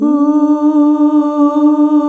UUUUH   D.wav